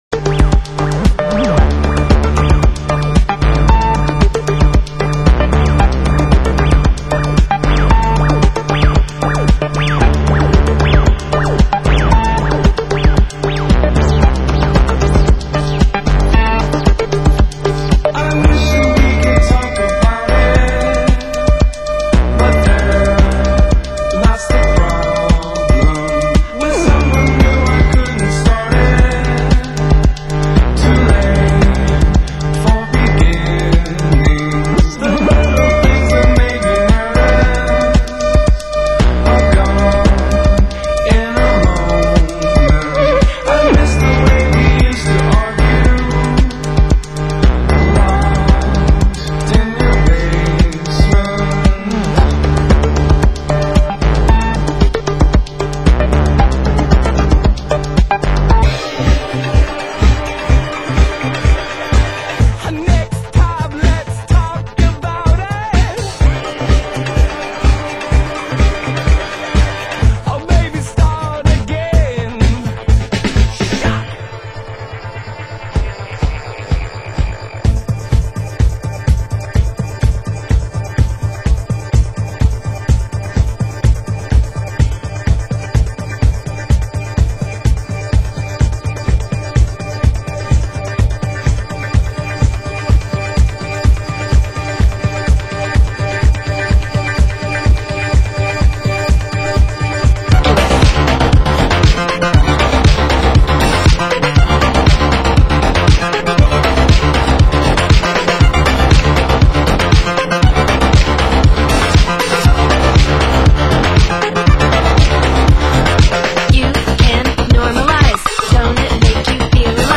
Genre: Leftfield